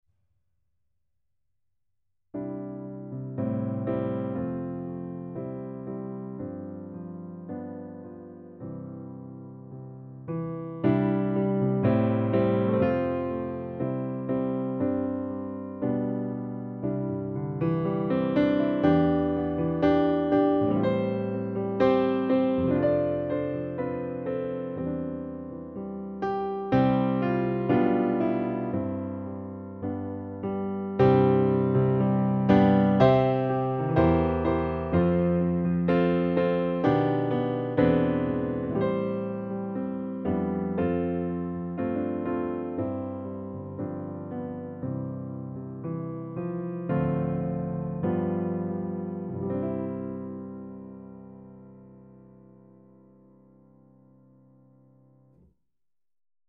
Hier eine Kostprobe eines unseres Pianisten.
Herr, segne uns und behüte uns (Piano